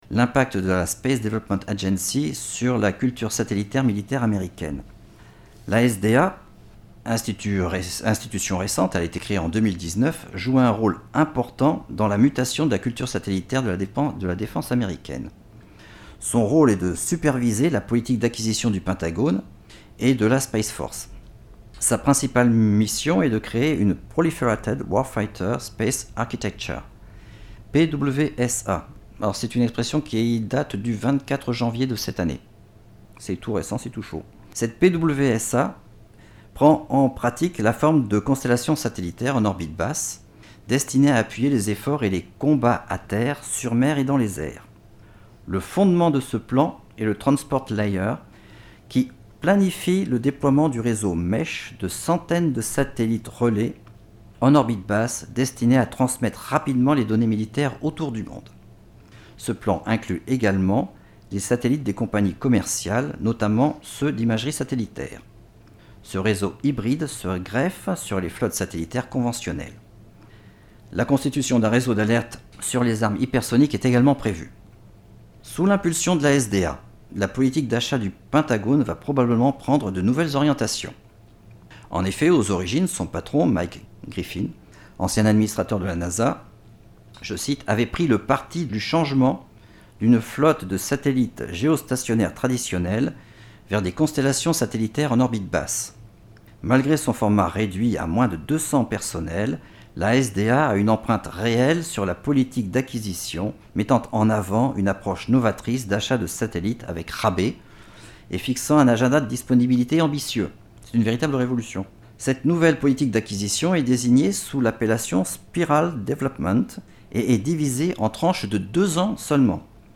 Une conférence en 8 épisodes audio :
La conférence vous est proposée en replay sous la forme d'une série audio en 8 épisodes.
Audio conférence CDEM IRSEM constellations satellitaires_6.mp3